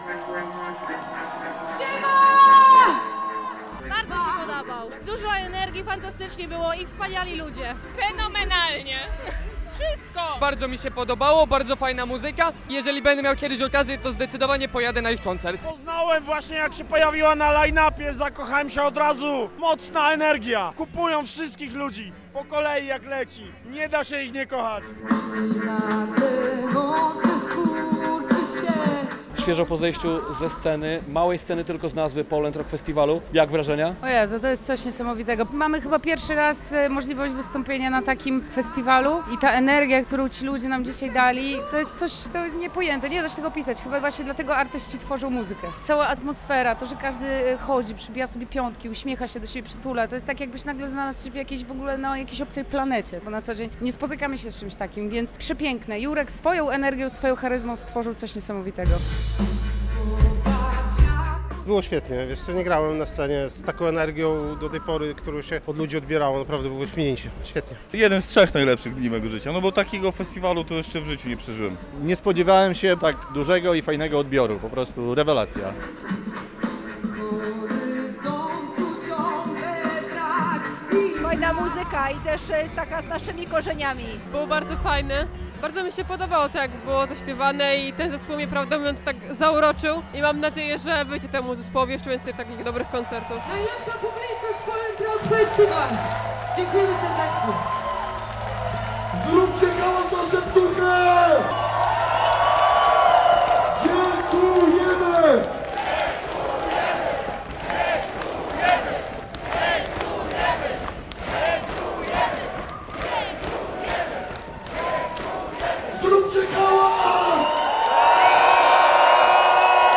Zespół Szeptucha wystąpił na Pol'and'Rock Festival - relacja